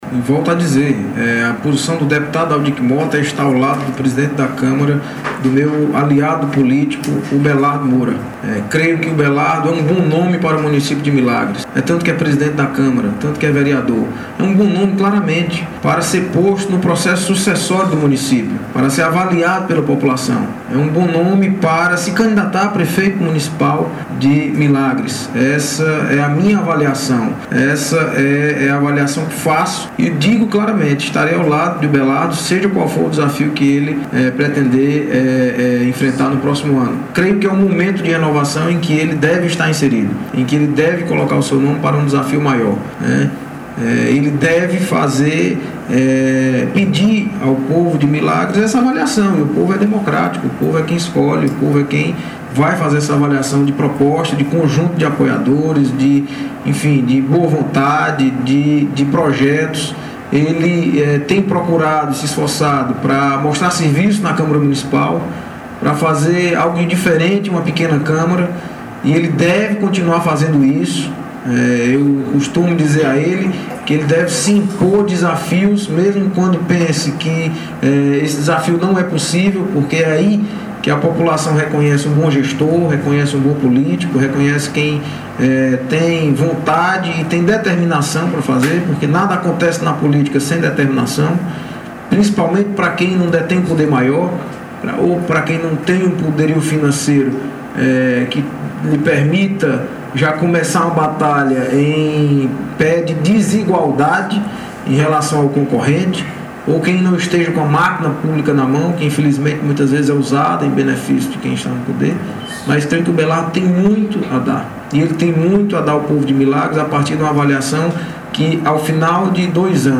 O fato aconteceu em uma entrevista concedida ao Jornal Ponto de Vista da Rádio Som da Terra FM.
Escute a fala de Deputado Aldic Mota no Jornal na Som da Terra FM: